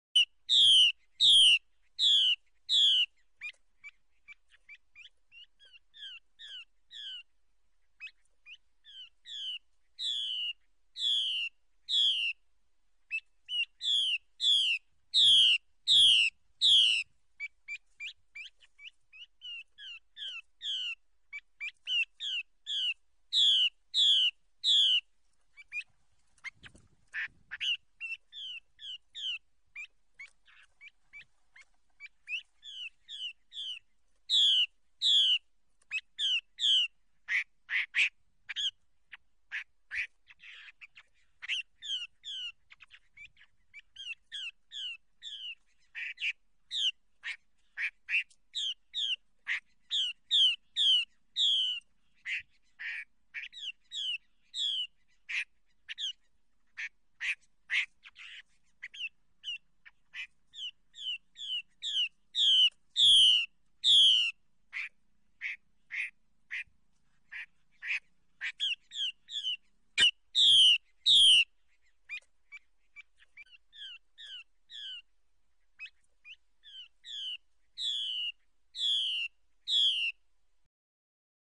画眉鸟叫声音频
画眉，也叫画眉鸟、中国画眉，属于噪鹛科，体重54-54克，体长21-21厘米。中型鸣禽。